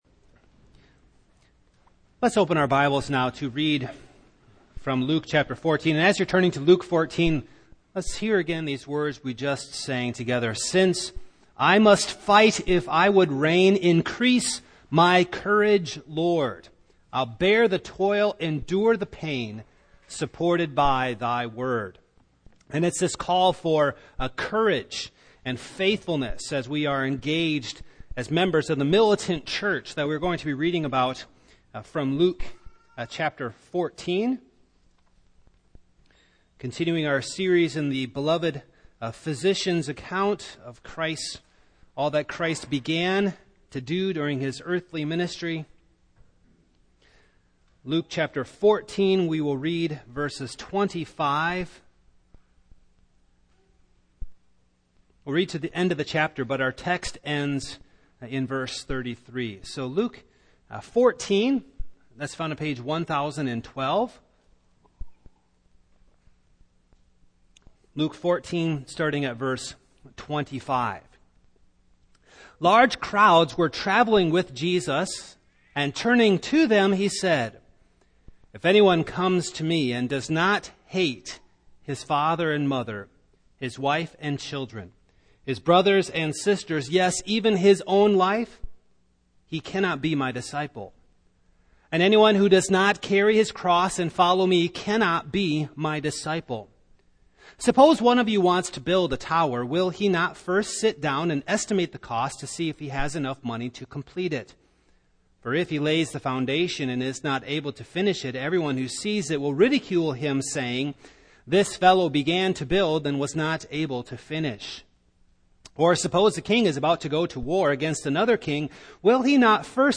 Passage: Luke 14:25-35 Service Type: Morning